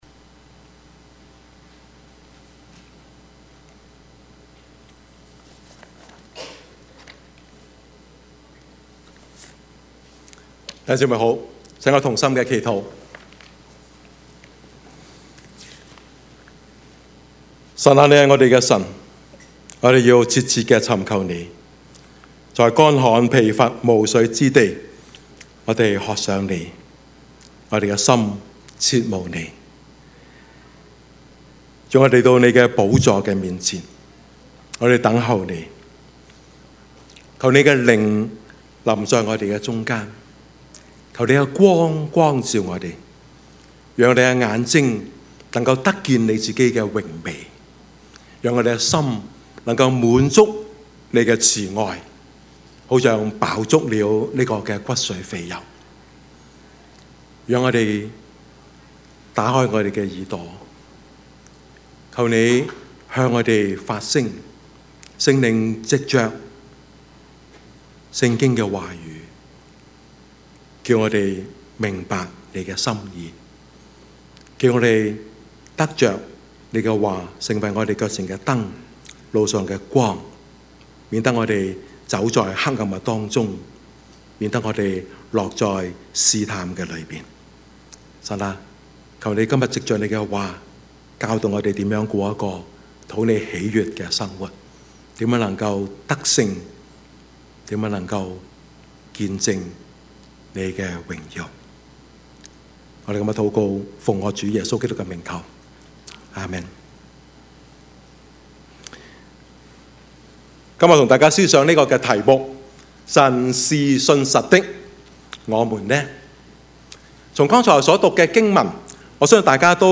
Service Type: 主日崇拜
Topics: 主日證道 « 虛有其表的事奉敬拜生活 虛有其表的事奉敬拜生活 »